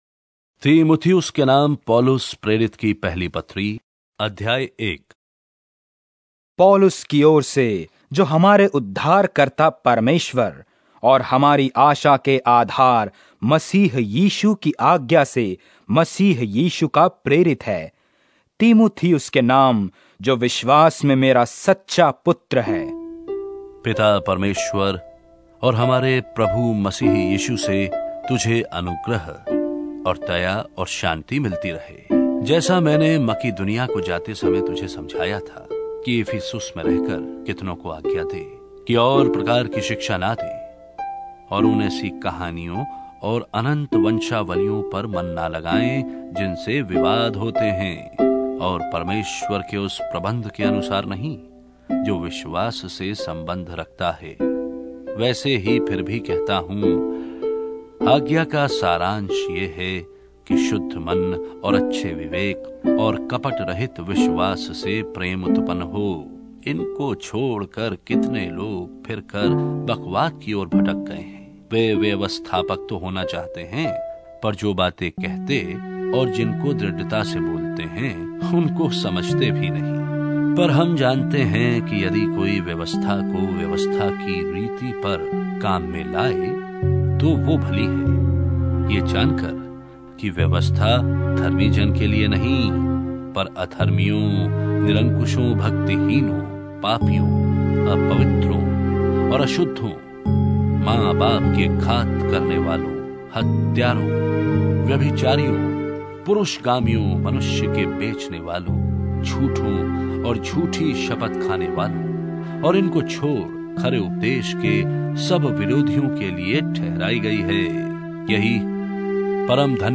Hindi Drama Audio Bible New Testament